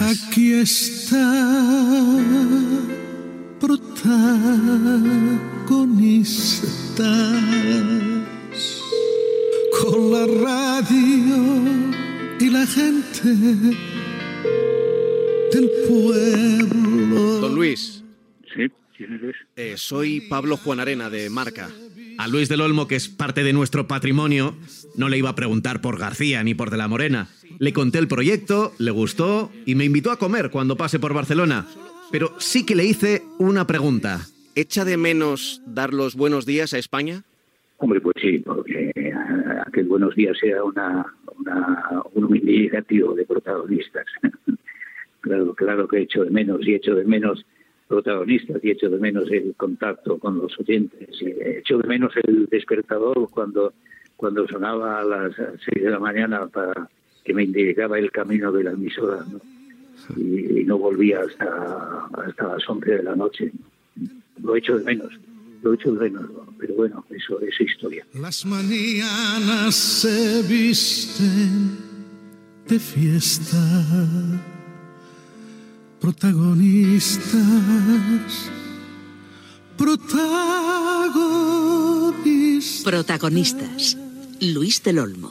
Jingle de "Protagonistas" cantat per Dyango i fragment d'una entrevista a Luis del Olmo, després de la seva jubilació.